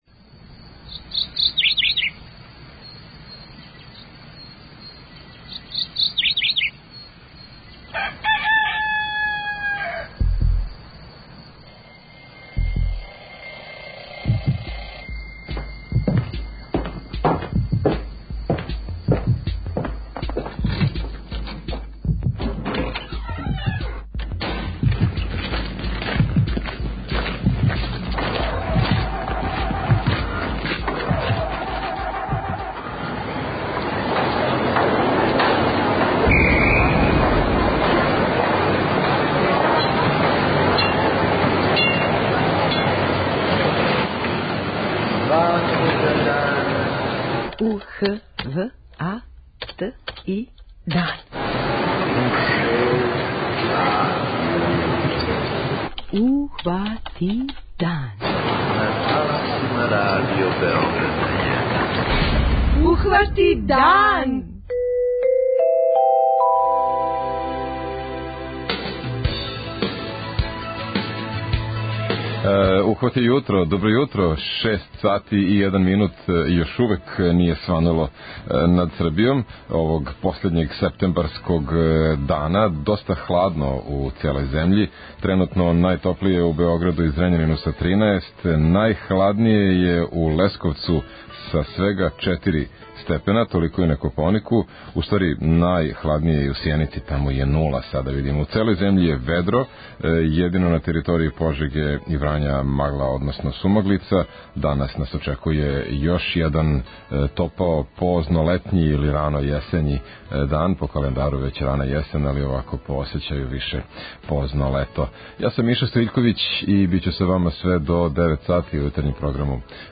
Слушаоци ће моћи да чују кратак преглед радионица које ће бити одржане од 18 сати па све до касних јутарњих сати, а дописник из Ниша детаљно ће нам представити шта ће се све дешавати у том граду.
преузми : 21.57 MB Ухвати дан Autor: Група аутора Јутарњи програм Радио Београда 1!